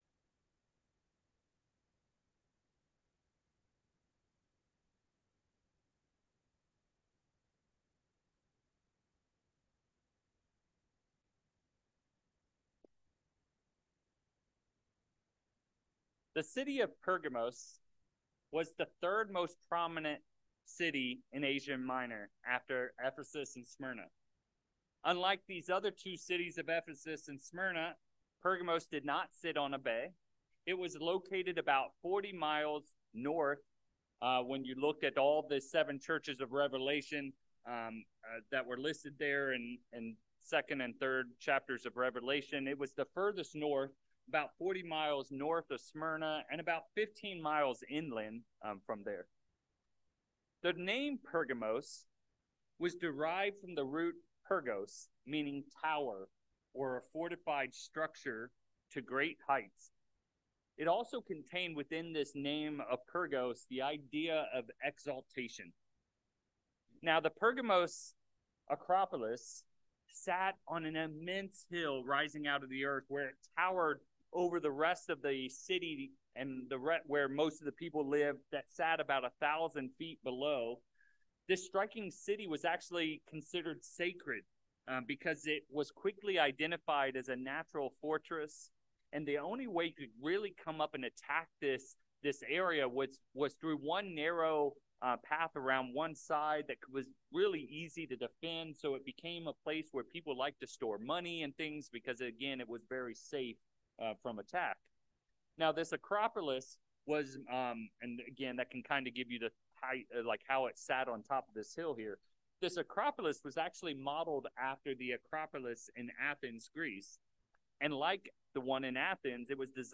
Orinda Pergamos was the center of the Roman government in Asia Minor. In this sermon, we’ll look at how Jesus Christ’s teaching to this congregation contrasted with this Roman authority center of Pergamos.